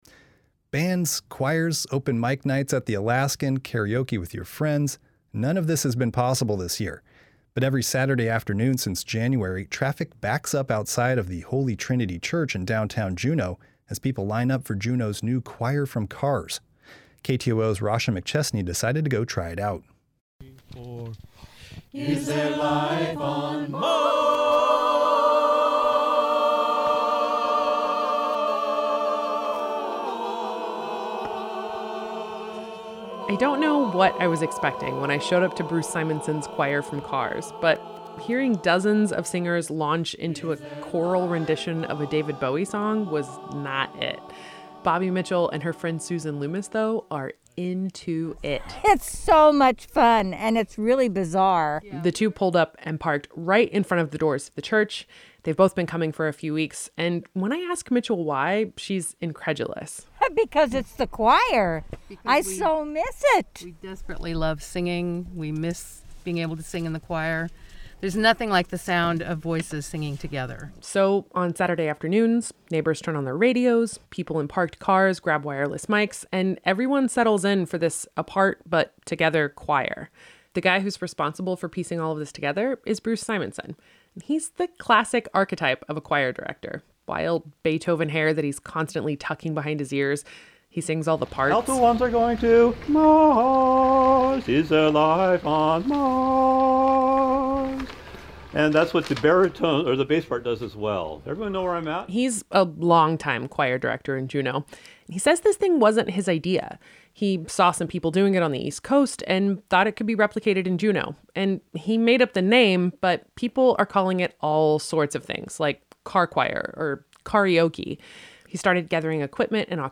They get a microphone and headphones and told to tune their car stereos to 107.1. Volunteers pass out sheet music.
The songs are a mix of choral and folk music.
And, for an hour and a half, if you tune in — it sounds like any other choir rehearsal with just a few more technical problems.
And at the end instead of clapping — everyone honks their horns.